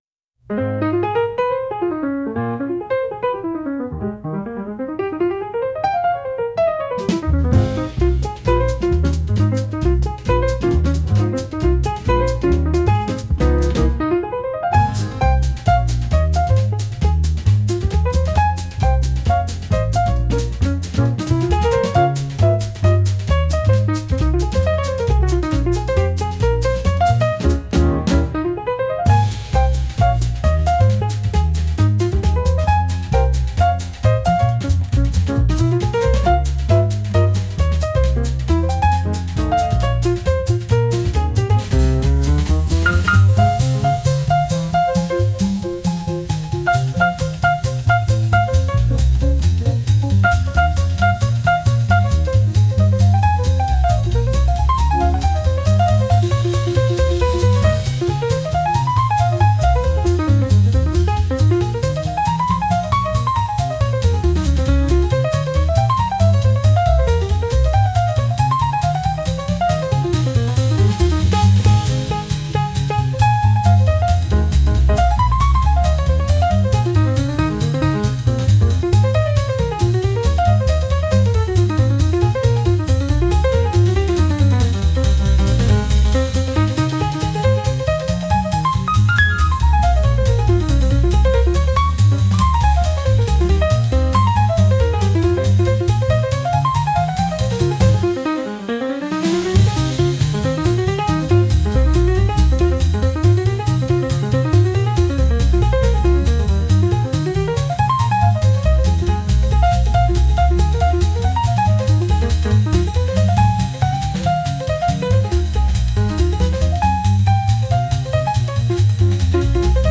軽快でハイテンポなジャズです。